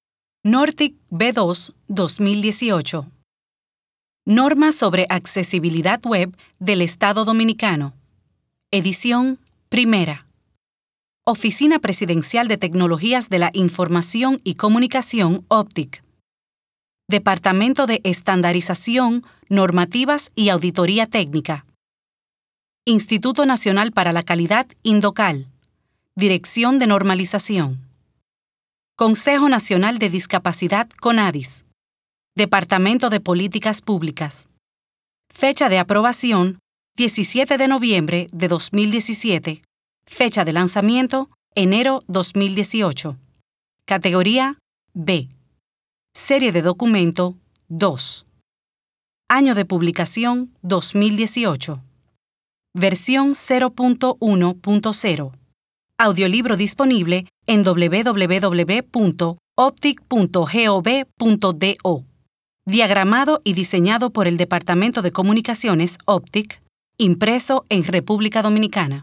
Audio Libro NORTIC B2 - Segunda página